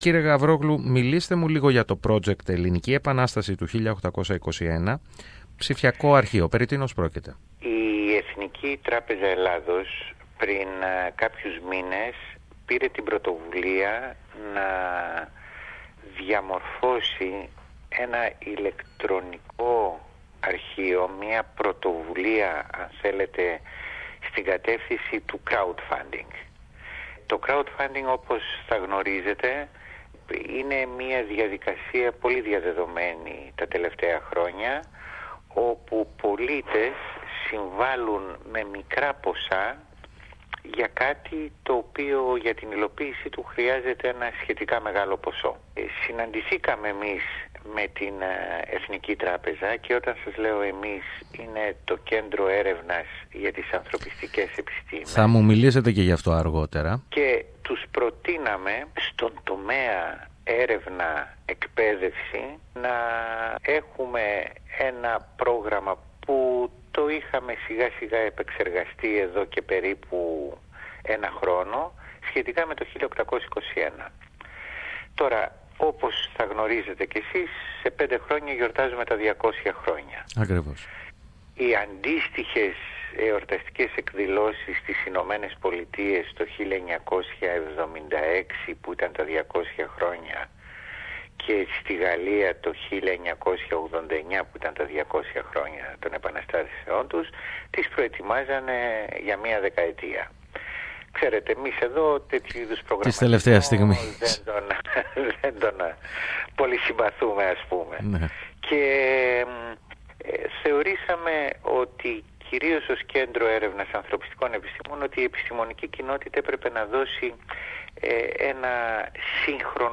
Το Ελληνικό Κέντρο Έρευνας για τις Ανθρωπιστικές Επιστήμες, έχει αναλάβει τη δημιουργία εκπαιδευτικής και ερευνητικής διαδικτυακής εφαρμογής με θέμα την Ελληνική Επανάσταση του 1821, με αφορμή την συμπλήρωση 200 ετών το 2021. Περισσότερα για το θέμα ακούμε από τον πρόεδρο του διοικητικού συμβουλίου του Κέντρου Έρευνας, Κώστα Γαβρόγλου,